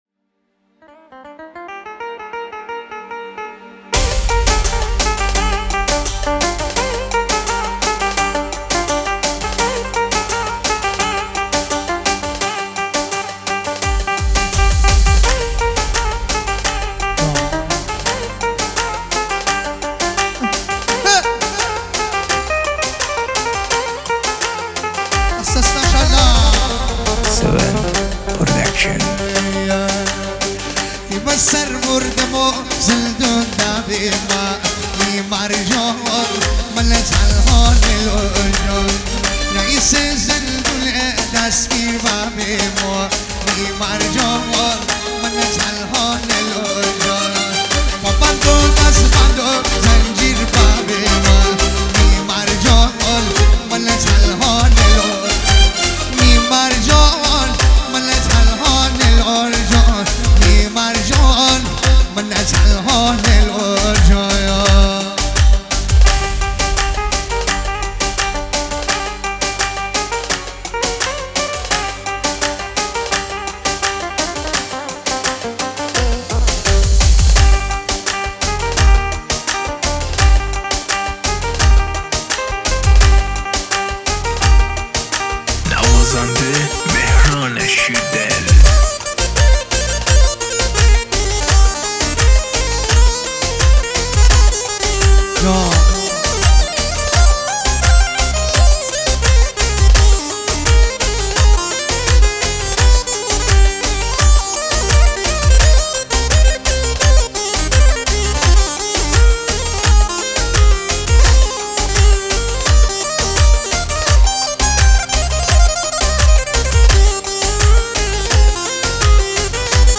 جشنی مجلسی